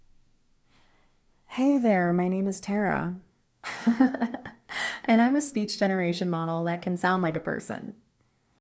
speech-synthesis text-to-speech
Orpheus 3B - high quality, emotive Text to Speech
"voice": "tara",